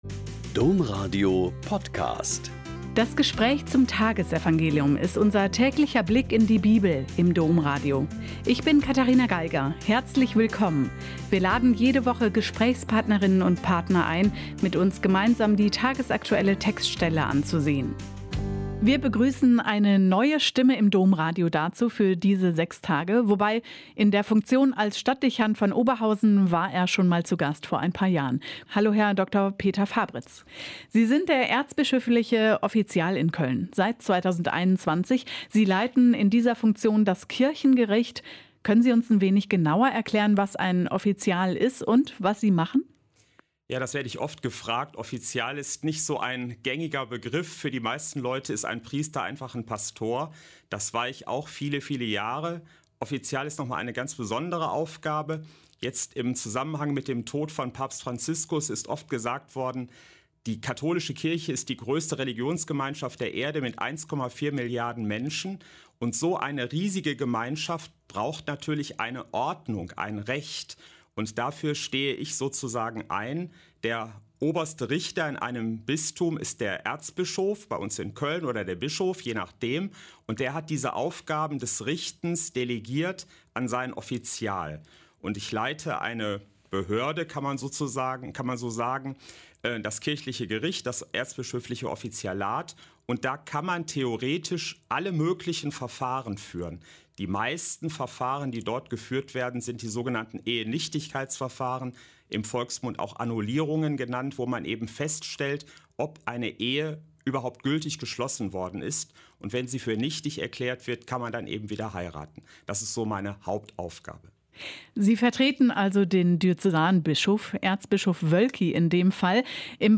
Joh 3,1-8 - Gespräch